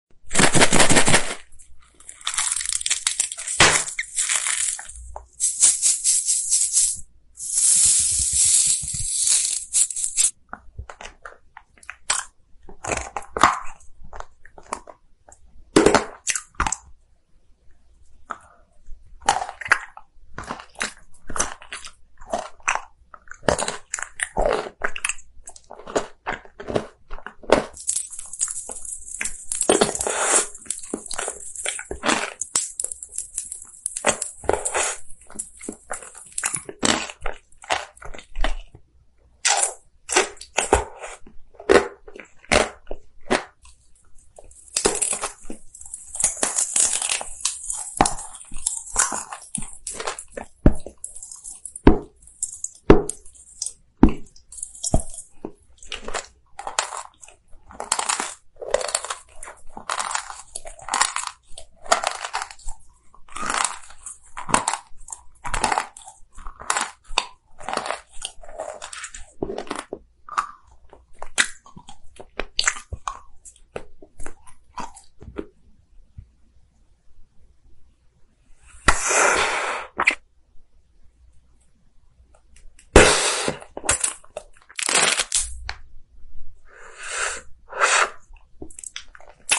ASMR satisfying eating emojis challenge